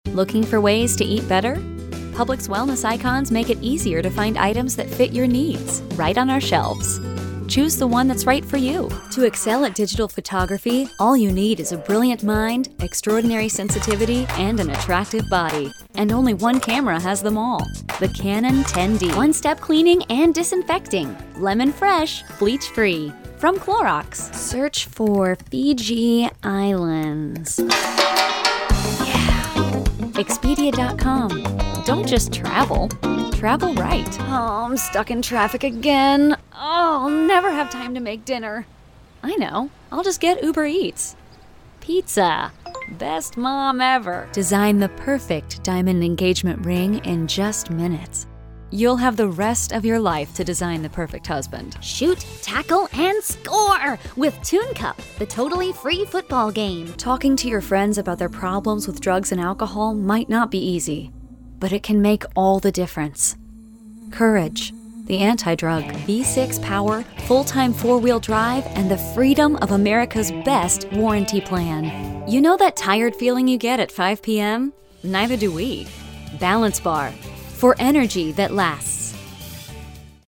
Female Voice Over, Dan Wachs Talent Agency.
Young Mom, Best Friend, Warm & Caring.
Commercial